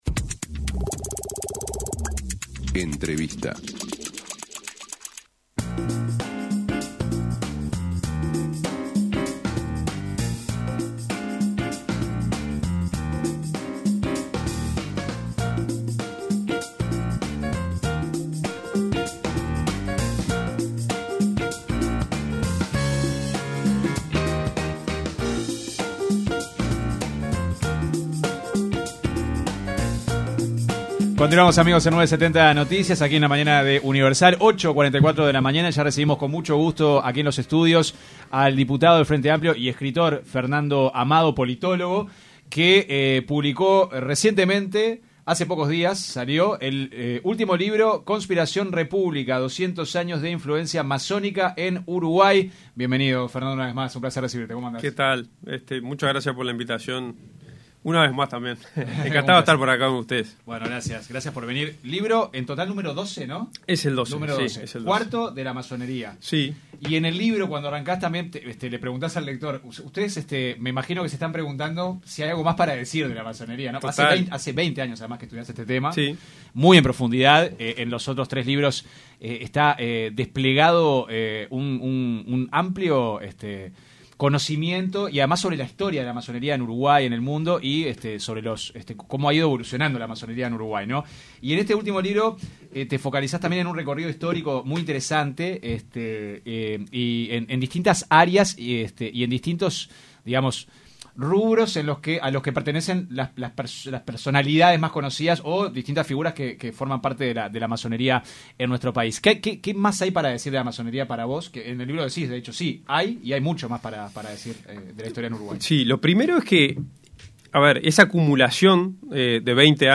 AUDIO El diputado del Frente Amplio y escritor, Fernando Amado, se refirió en diálogo con 970 Noticias, al último libro que escribió, relacionado con la masonería, y donde refleja los nombres de altos cargos del Poder Ejecutivo.